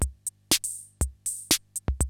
CR-68 LOOPS3 2.wav